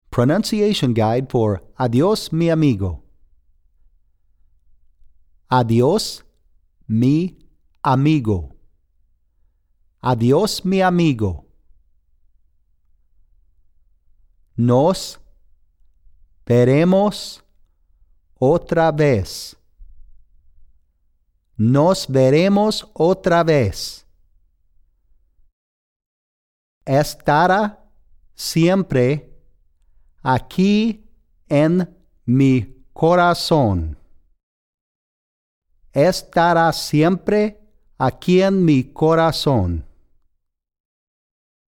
Adiós Mi Amigo - Pronunciation Guide MP3
To help you teach the song "Adiós Mi Amigo," from Music K-8, Vol. 16, No. 5, we're offering this spoken pronunciation guide as a free downloadable MP3.
Adios_Pronunciation.mp3